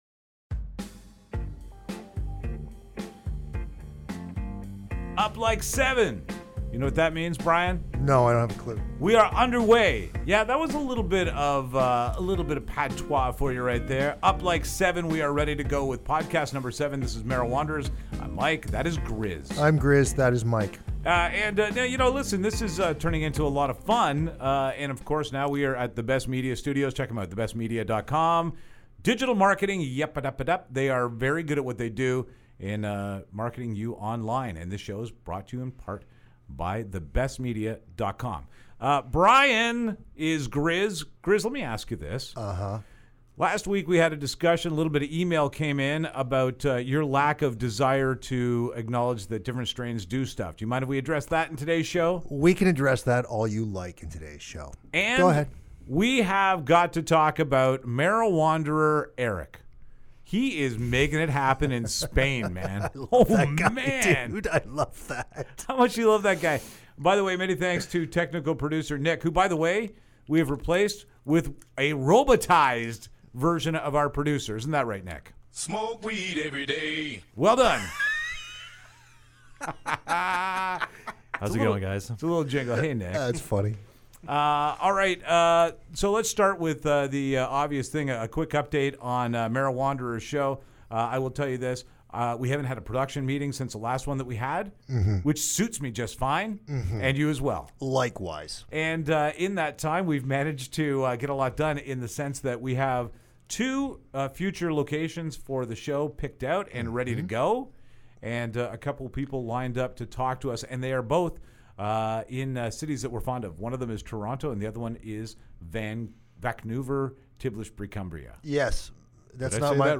Mariwanderers Episode 7 - Soundboard Antics Galore!